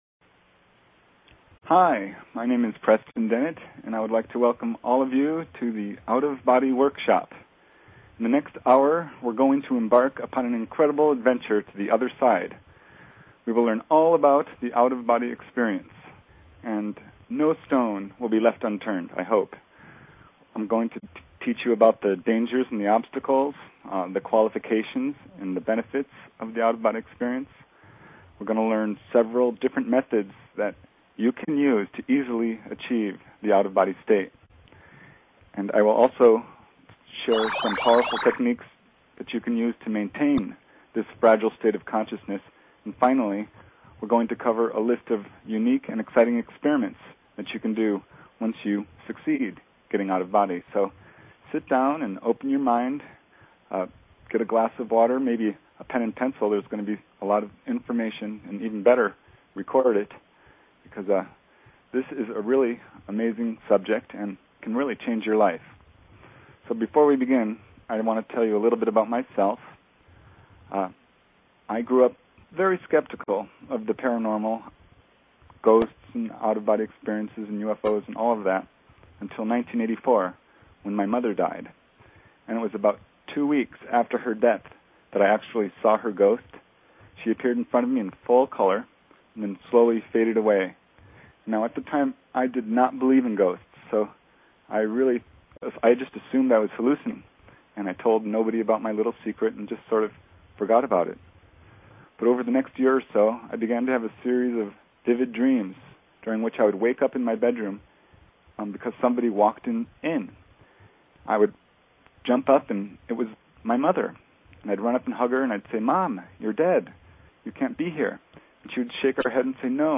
Talk Show Episode, Audio Podcast, Wildcard_Fridays and Courtesy of BBS Radio on , show guests , about , categorized as
Wildcard Fridays with various Hosts on BBS Radio!